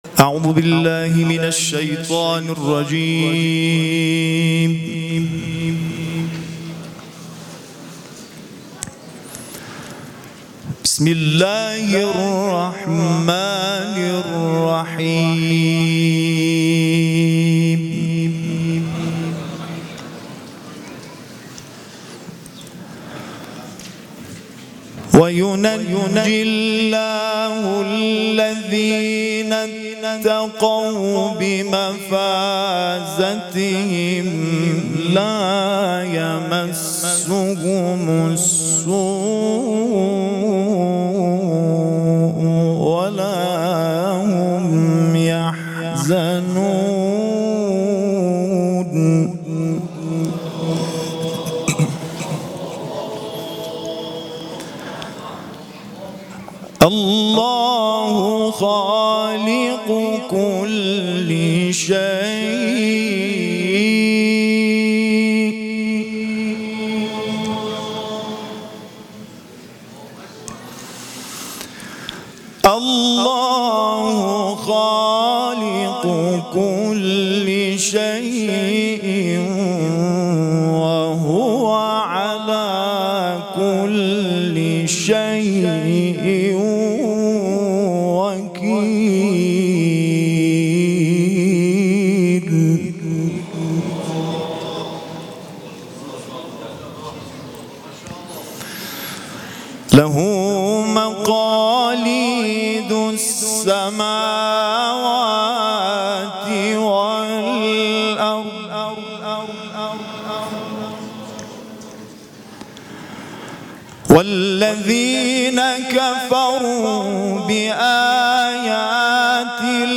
قاری بین‌المللی کشورمان در گردهمایی قاریان و دعاخوانان ممتاز کشور به تلاوت آیاتی از کلام‌الله مجید پرداخت.